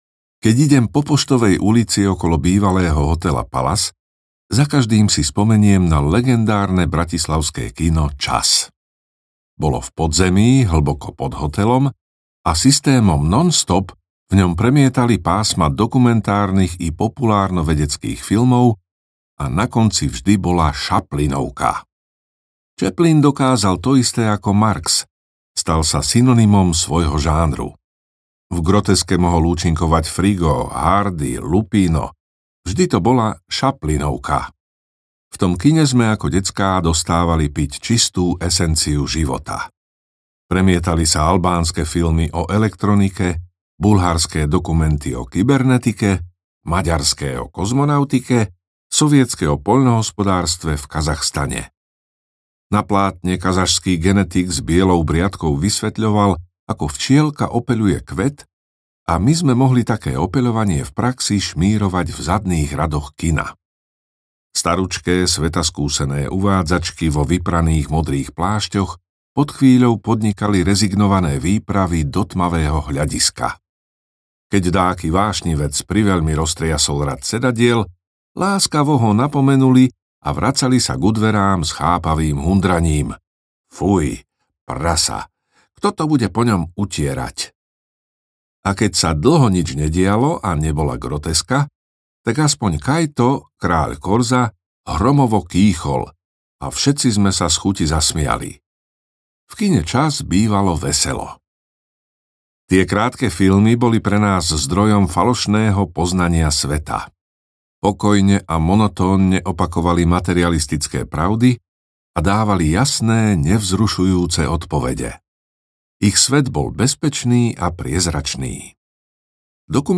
Bratislavské krutosti audiokniha
Ukázka z knihy
• InterpretIvo Gogál